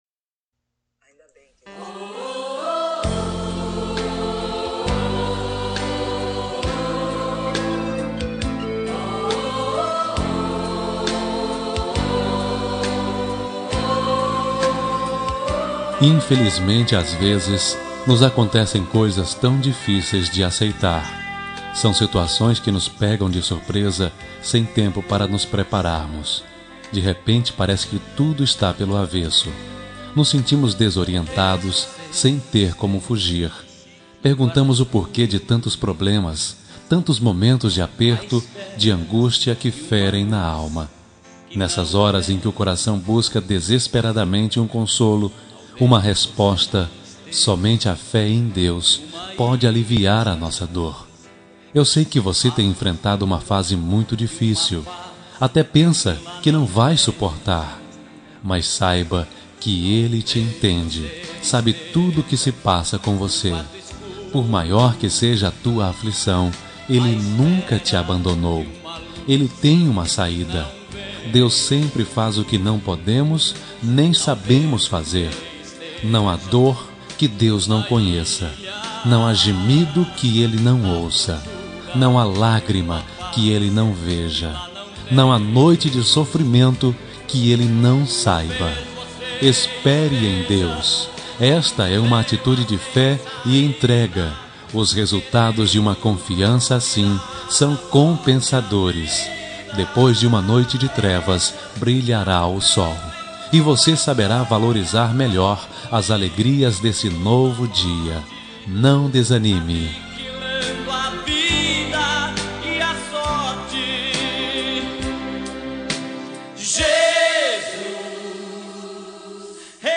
Telemensagem de Otimismo – Voz Masculina – Cód: 193 – Linda